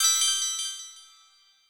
Special & Powerup (7).wav